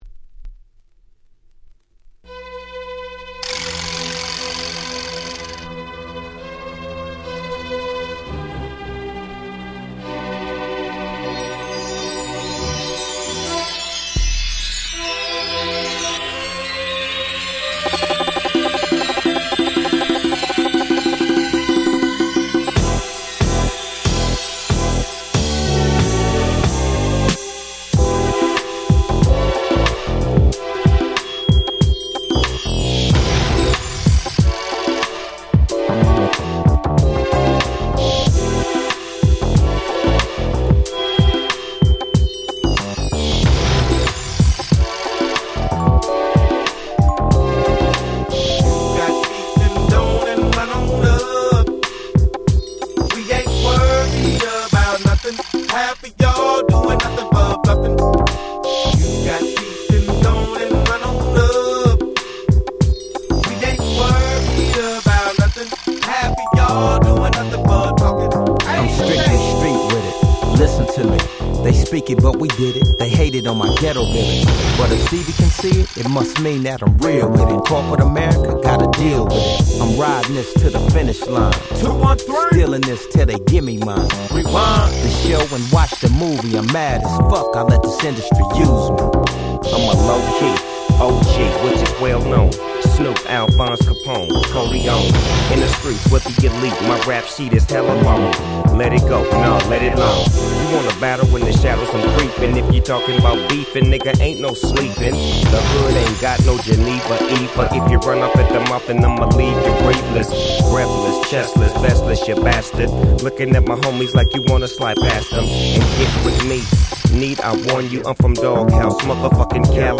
04' Super Nice West Coast Hip Hop / R&B !!